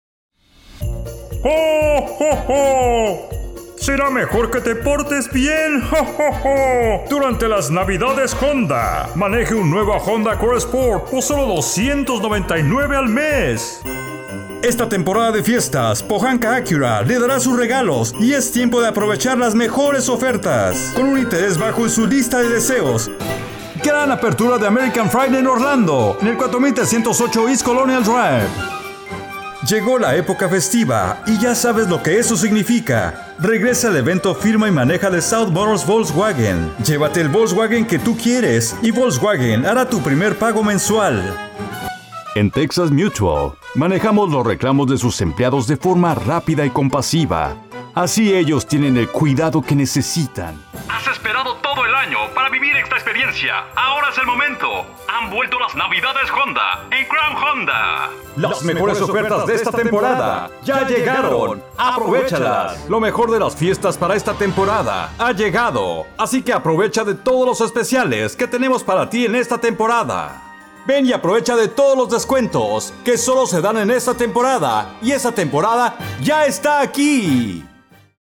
1120Male_Demo_.mp3